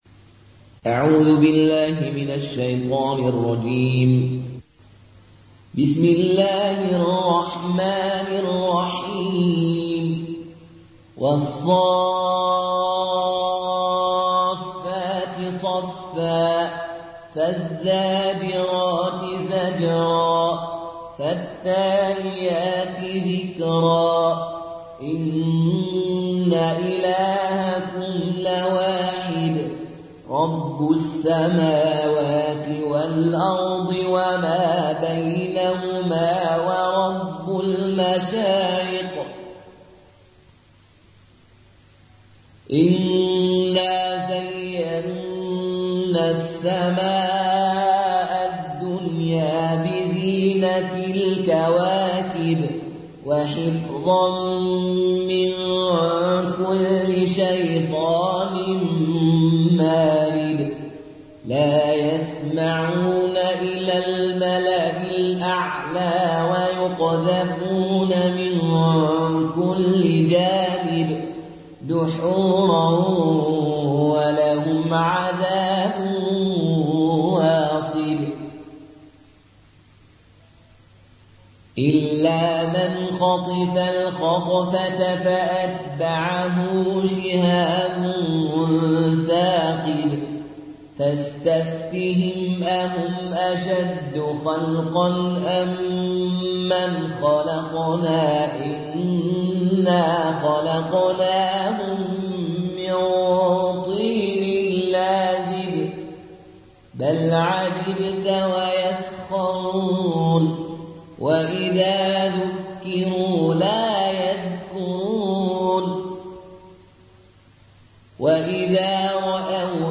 Riwayat Qaloon an Nafi